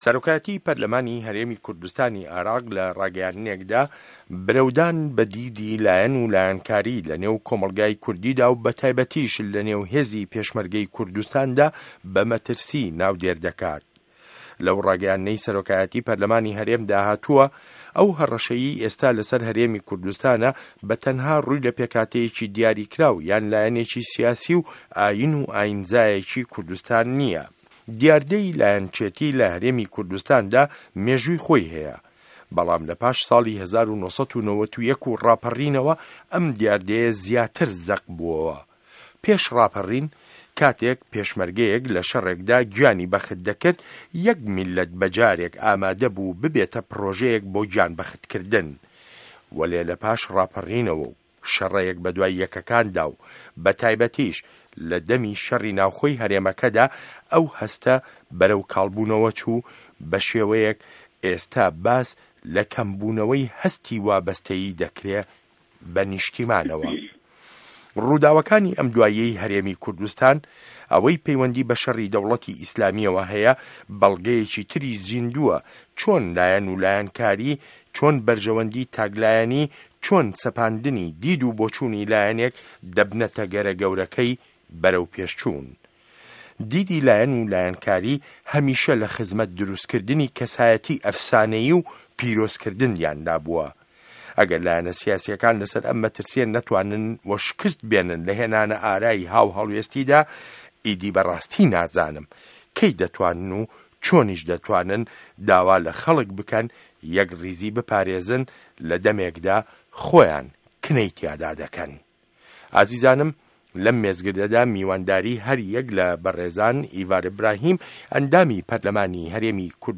مێزگرد: کاڵبوونه‌وه‌ی وابه‌سته‌یی به‌ نیشتیمانه‌وه